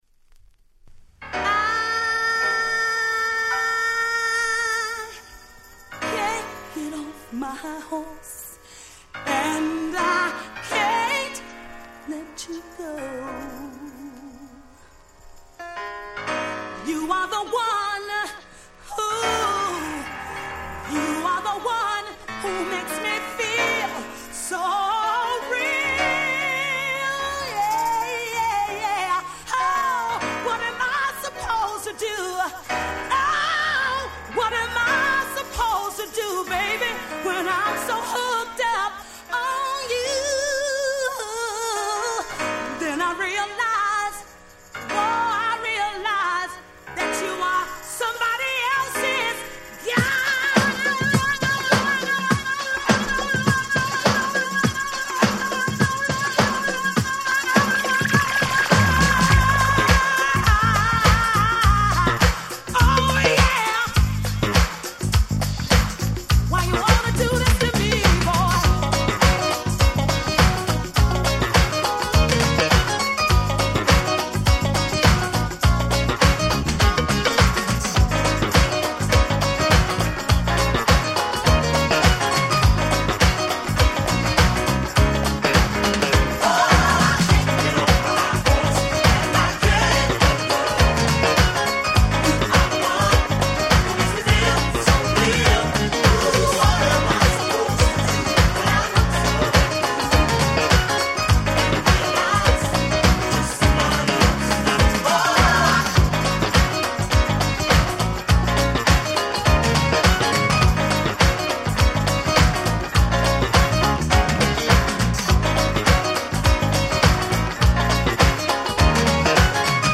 Super Dance Classics !!